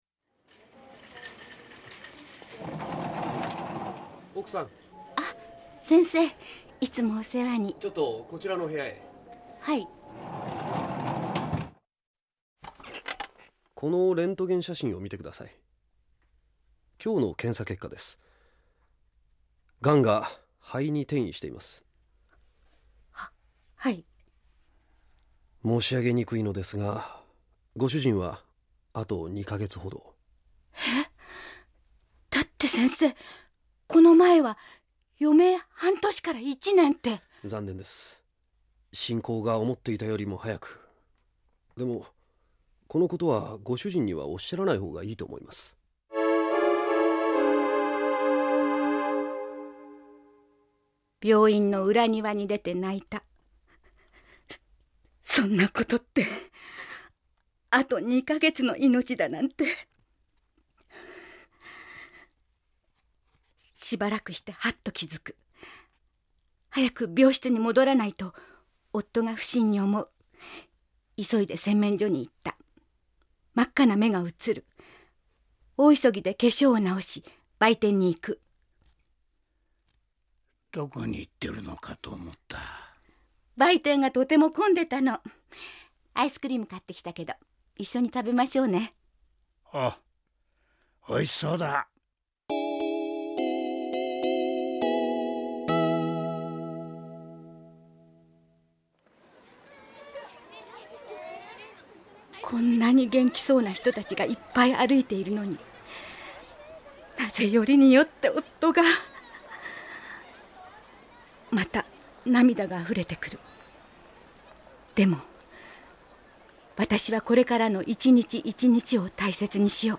●ラジオドラマ「LIFE」
・医師　（男性）